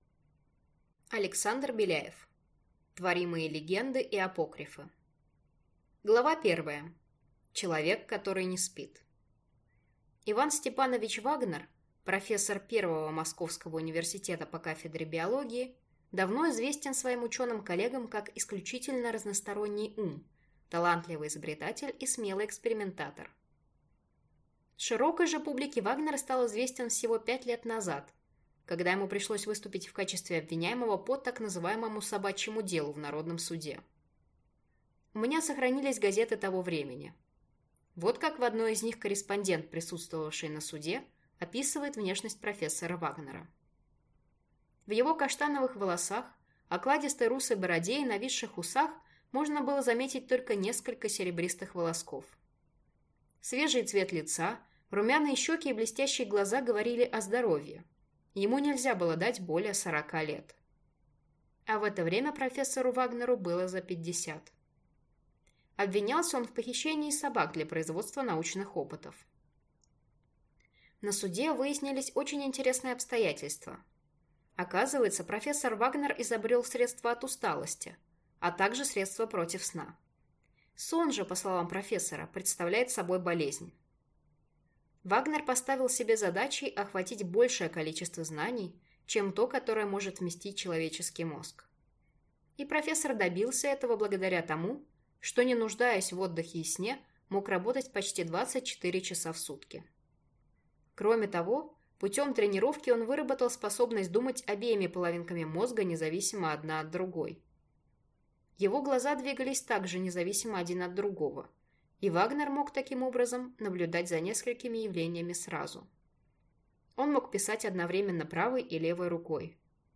Аудиокнига Творимые легенды и апокрифы | Библиотека аудиокниг